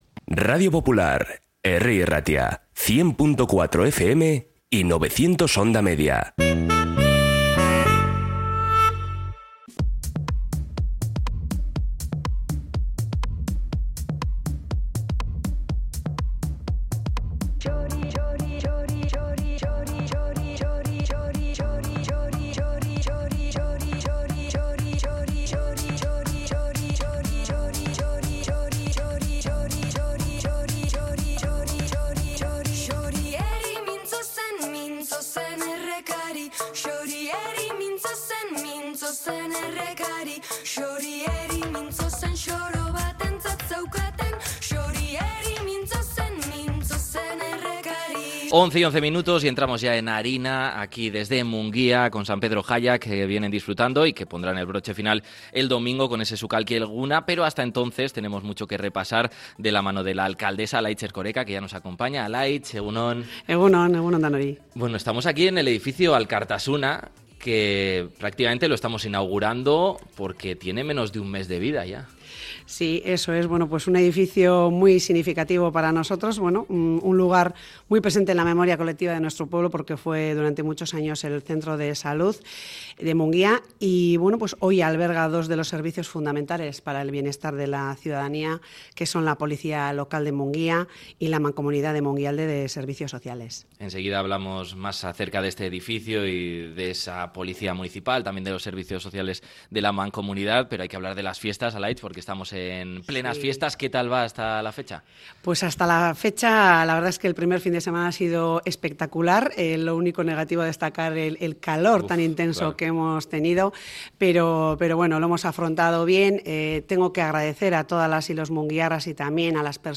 Programa especial desde la nueva sede de la Policía Local de Mungia y de la Mancomunidad Mungialde de Servicios Sociales